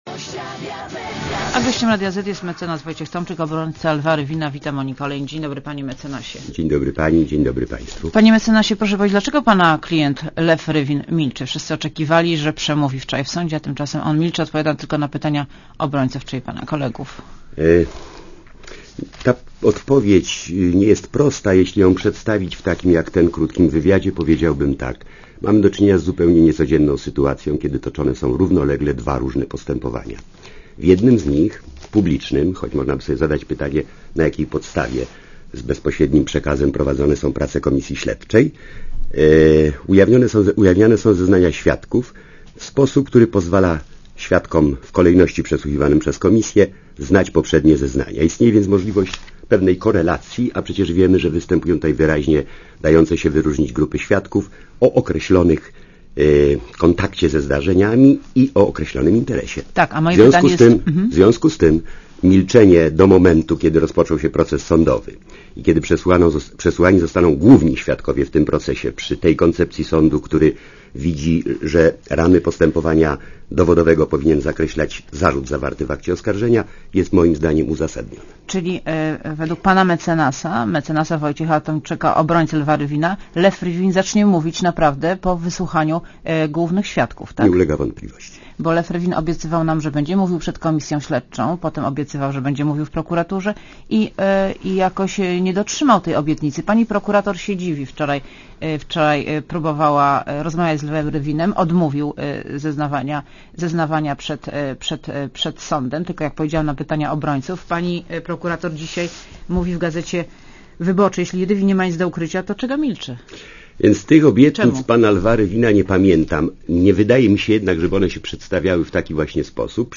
Wita Monika Olejnik, dzień dobry panie mecenasie.